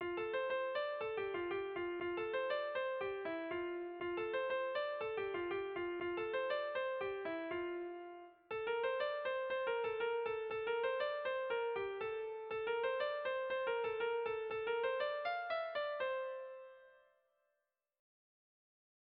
Tragikoa
A-A-B1-B2